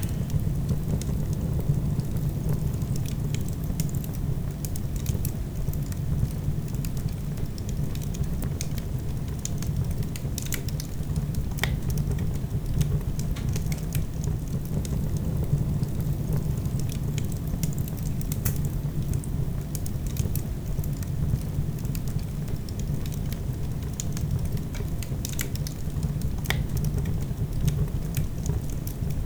fire_loop.wav